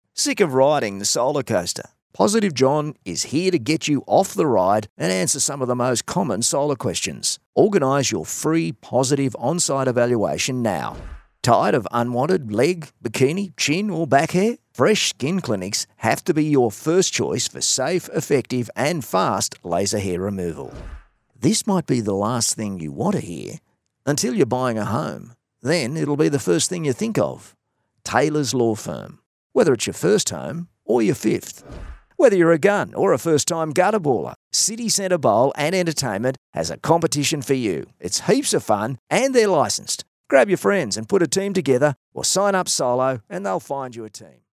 But pretty much specialises in the laid back Aussie style, relaxed , real and believable!
• Natural
• Natural Aussie Bloke